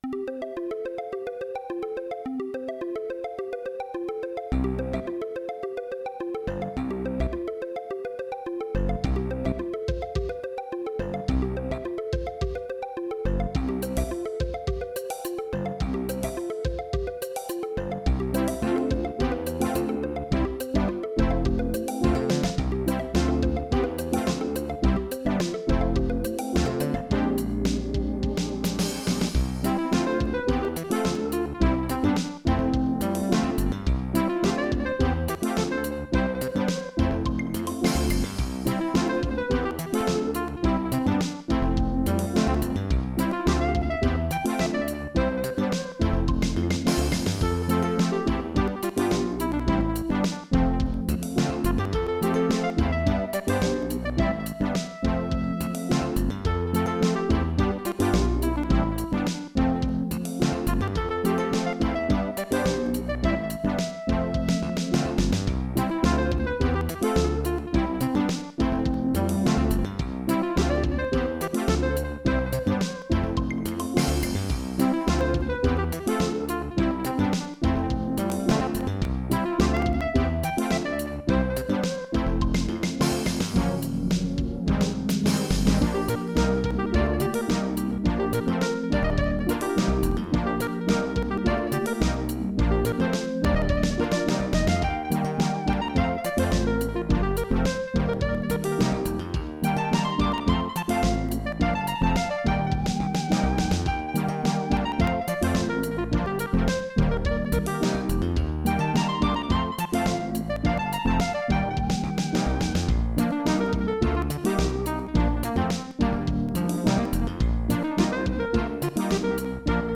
Roland LAPC-I
* Some records contain clicks.